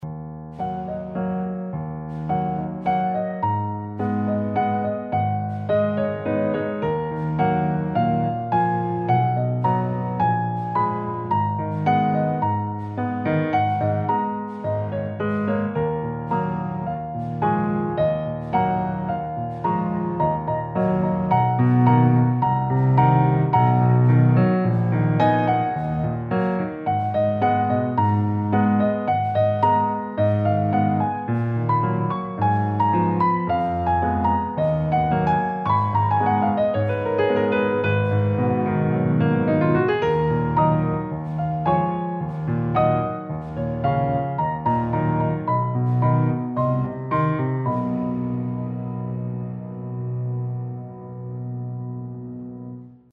Largo [0-10] - - amour - romantique - jazzy - doux - nuit